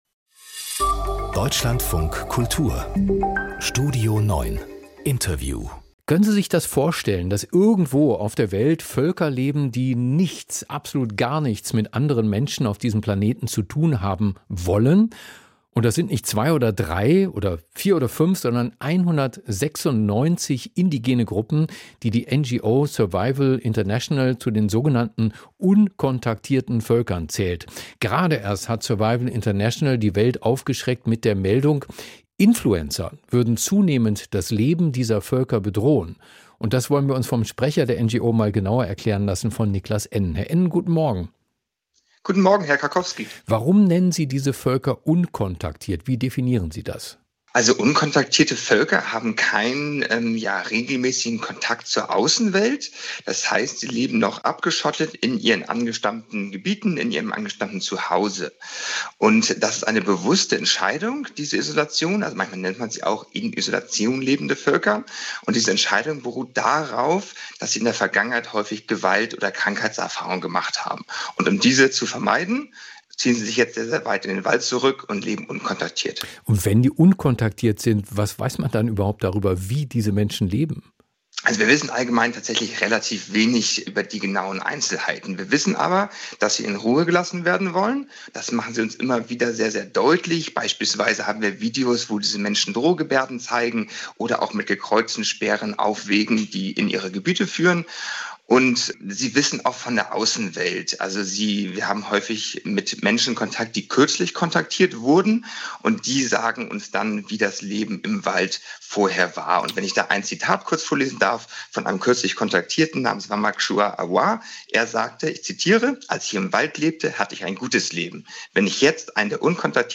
Podcast: Interview